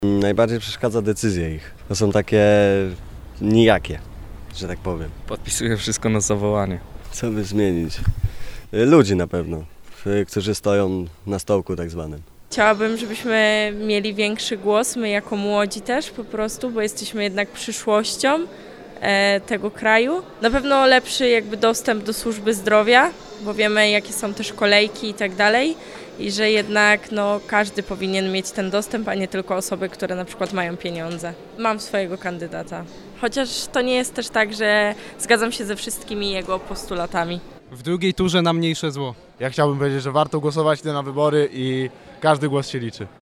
– My jako młodzi też jesteśmy przyszłością tego kraju – mówi jedna z uczestniczek.